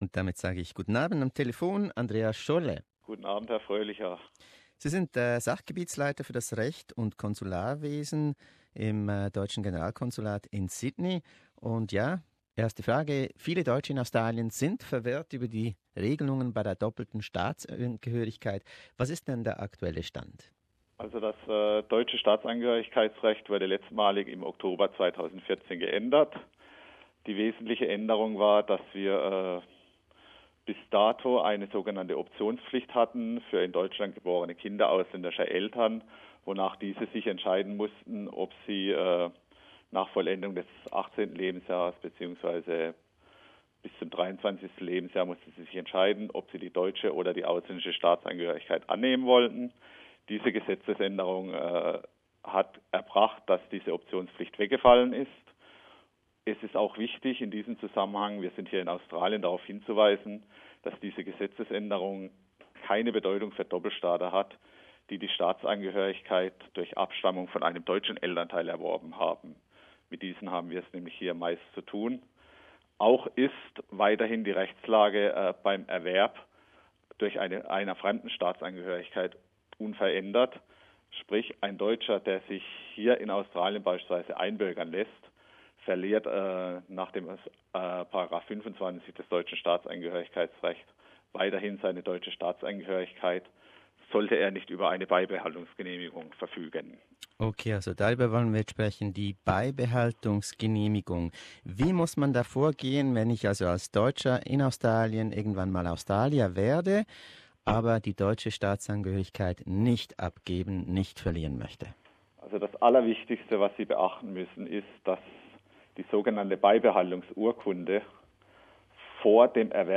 In diesem Interview erklärt ein Experte in Sydney die komplexen Regeln zur doppelten Staatsbürgerschaft.